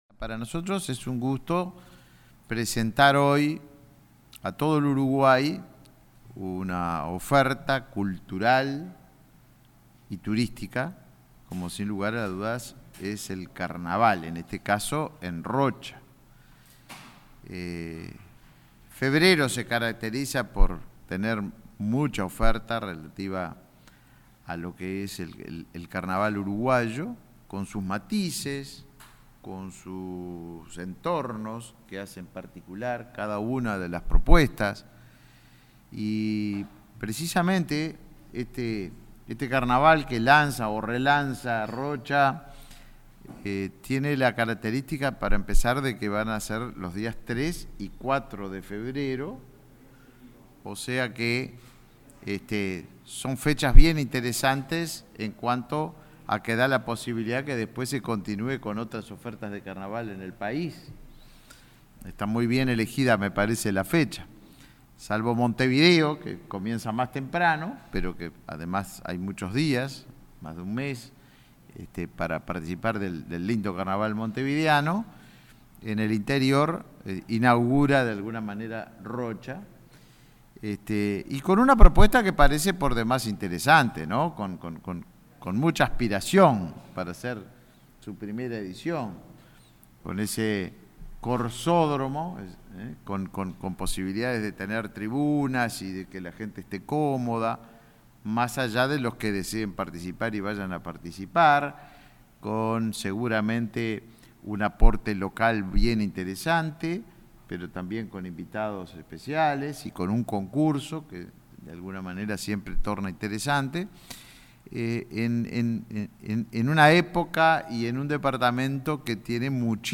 Palabras del ministro de Turismo, Tabaré Viera
El ministro de Turismo, Tabaré Viera, participó este lunes 30 en Rocha, del lanzamiento del Carnaval en ese departamento del este del país.